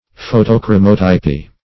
Search Result for " photochromotypy" : The Collaborative International Dictionary of English v.0.48: Photochromotypy \Pho`to*chro"mo*typ`y\, n. The art of making photochromotypes.